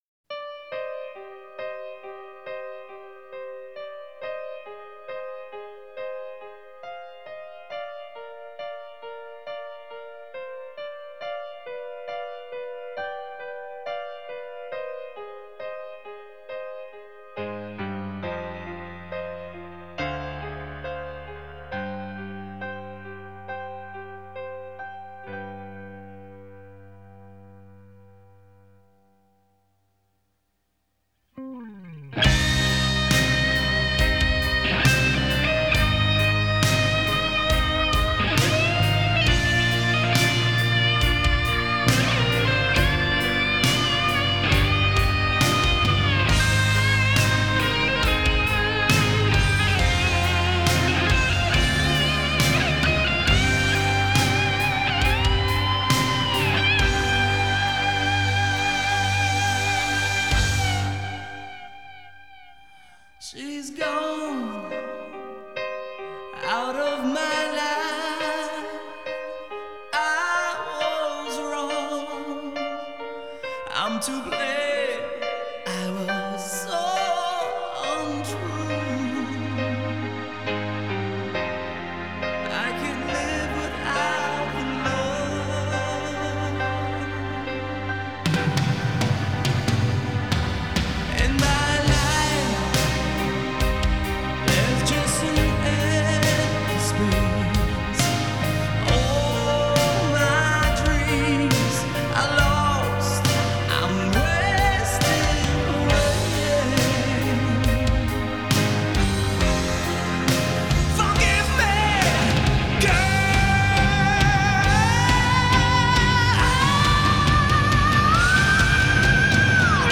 فوق العاده غمناک برای عاشقان